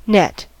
net: Wikimedia Commons US English Pronunciations
En-us-net.WAV